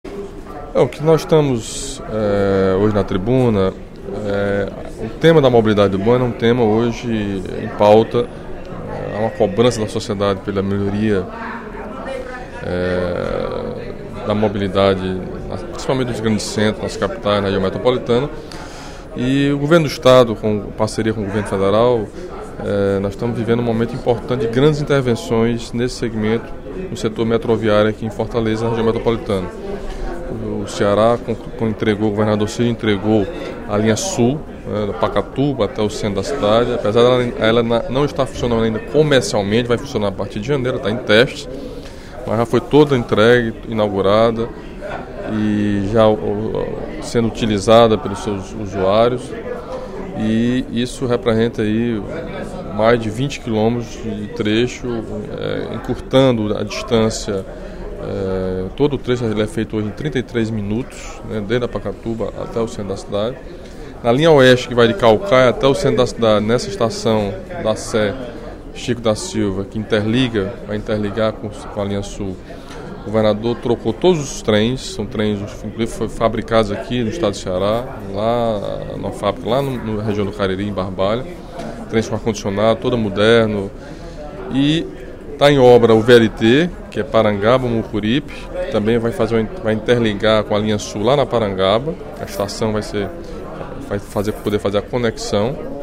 Durante o primeiro expediente da sessão plenária desta quinta-feira (21/11), o deputado Camilo Santana (PT) destacou as ações do Executivo cearense em parceria com o Governo Federal. O parlamentar citou o metrô de Fortaleza, considerado um dos maiores projetos de mobilidade urbana do Brasil.